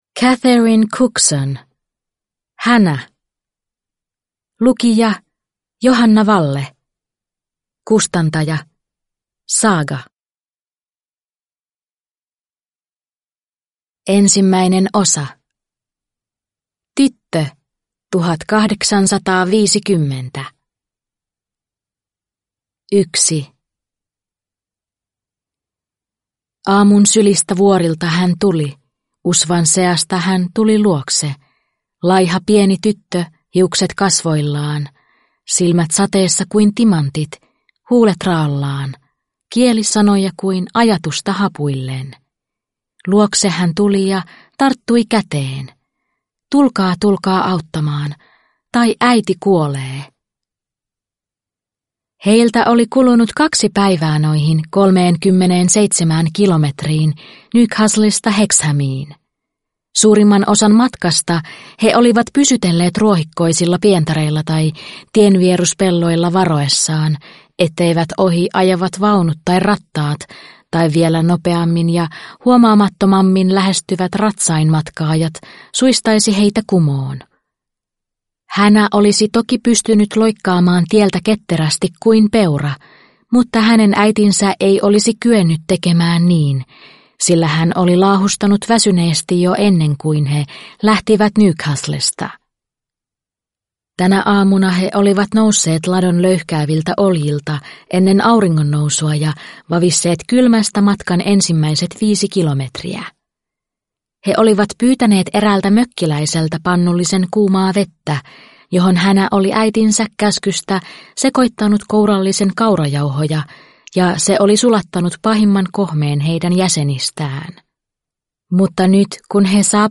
Hannah (ljudbok) av Catherine Cookson